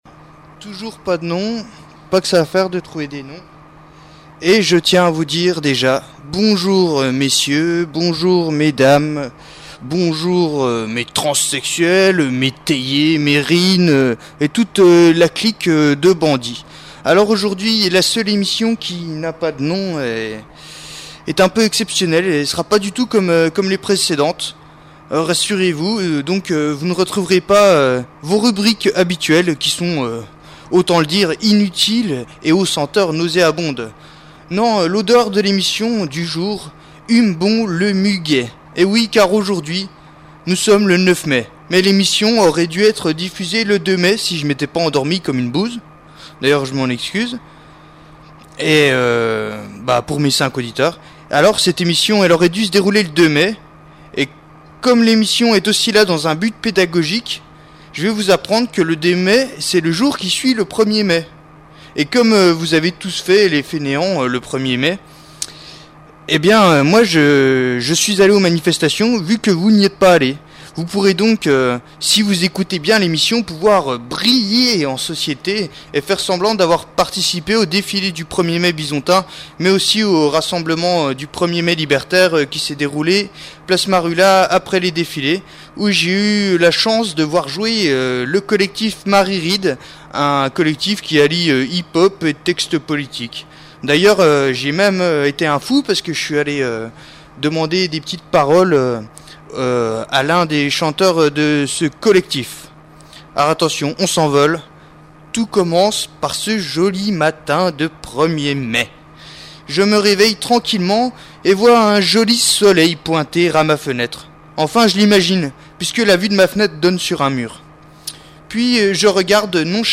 Enfin le reportage du 1er mai a été diffusé ce matin sur Radio Bip, et le podcast est déjà là.